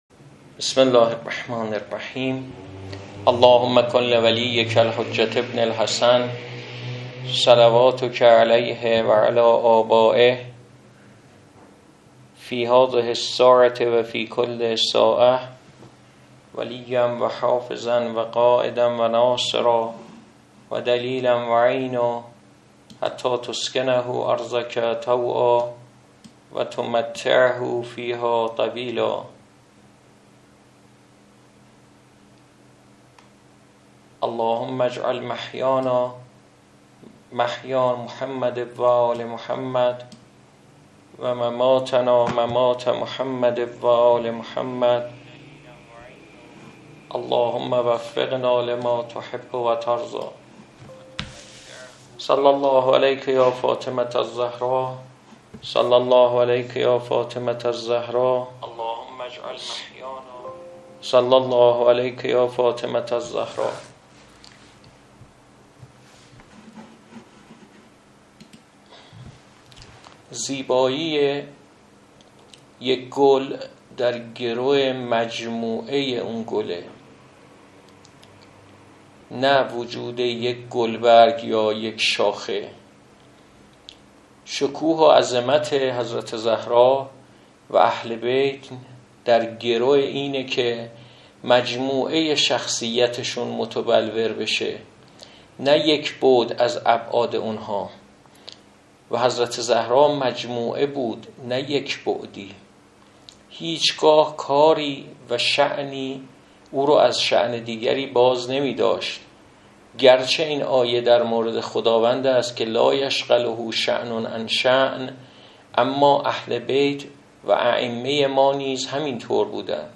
سخنرانی گردهمایی 29 آبان‌ماه 1404